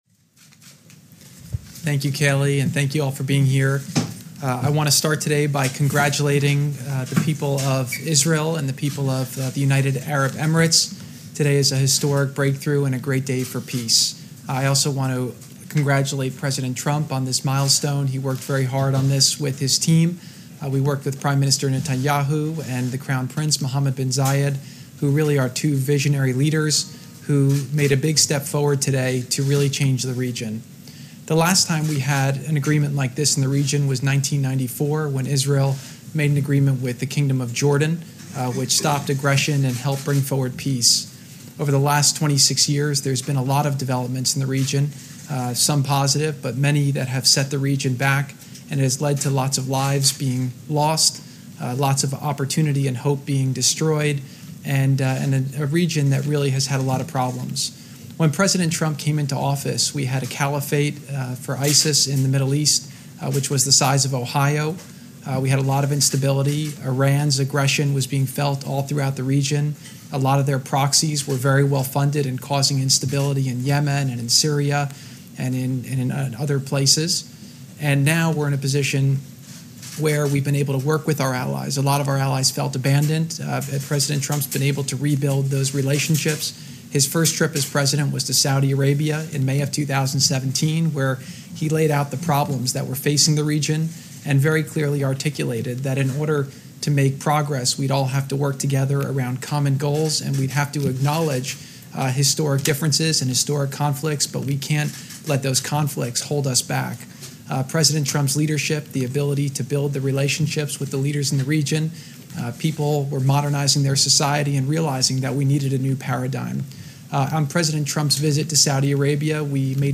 Jared Kushner and Robert O'Brien - White House Briefing on Israel-UAE Peace Deal (text-audio-video)